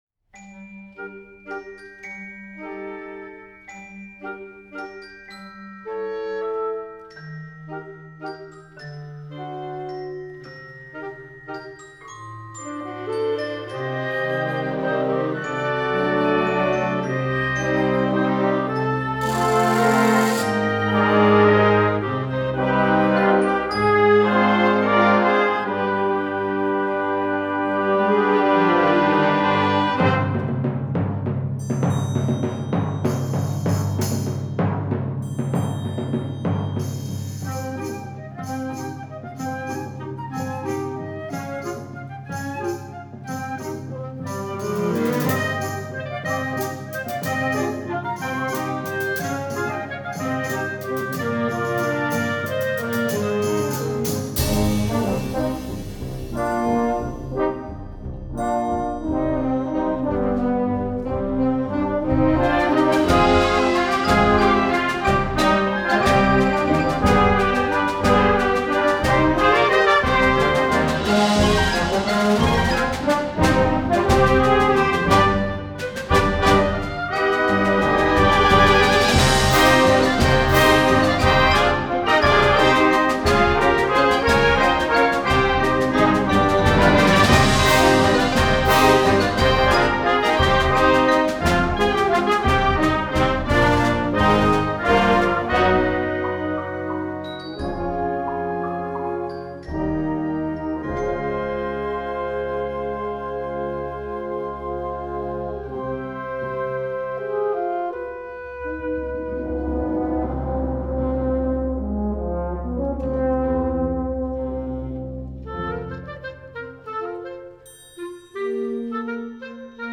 Concert & Festival